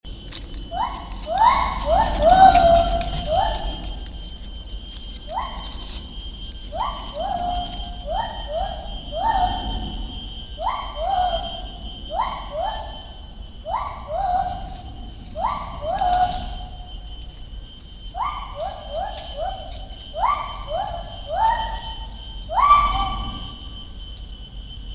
White-handed Gibbon  Hylobates lar
Play call
Its unmistakable call - a loud, whooping sound - can be heard from a great distance, especially when active in the morning.
white-handed-gibbon.mp3